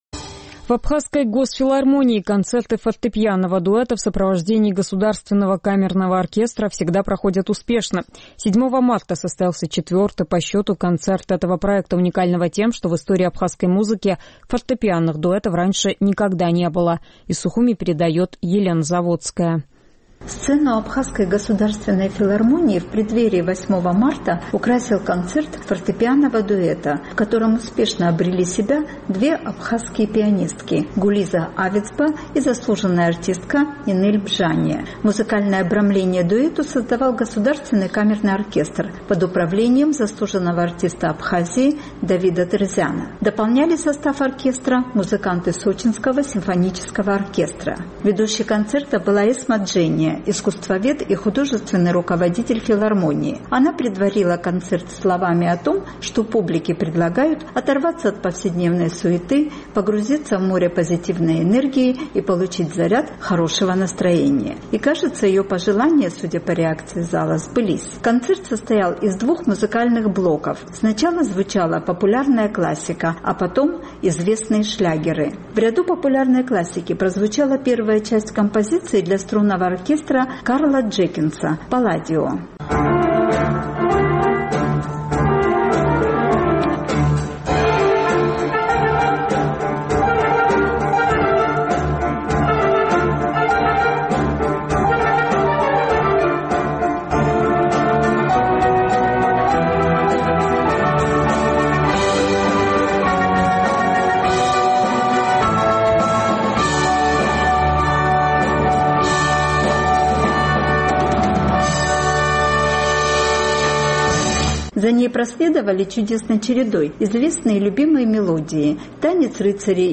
В Абхазской государственной филармонии концерты фортепианного дуэта в сопровождении Государственного камерного оркестра всегда проходят успешно. 7 марта состоялся четвертый по счету концерт этого проекта, уникального тем, что в истории абхазской музыки фортепианных дуэтов раньше никогда не было.
Концерт состоял из двух музыкальных блоков: сначала звучала популярная классика, а затем – известные шлягеры.